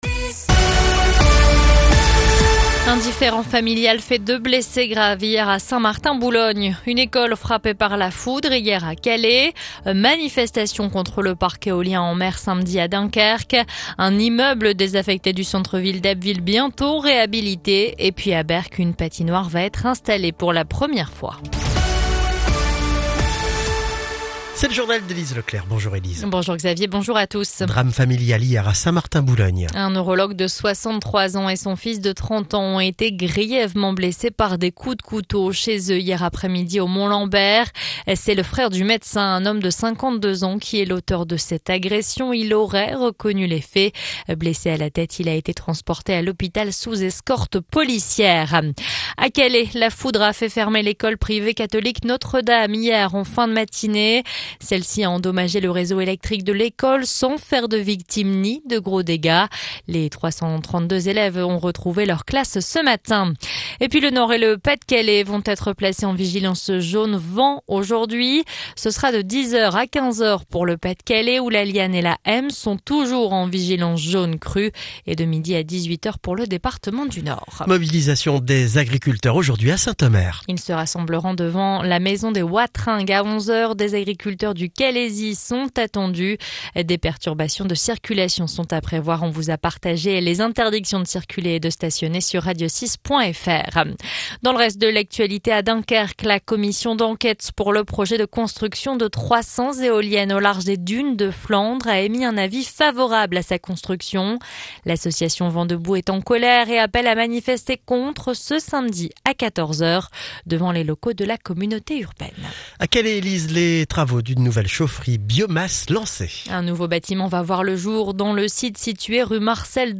Le journal du mercredi 27 novembre 2024